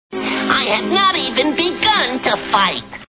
Looney Toons TV Show Sound Bites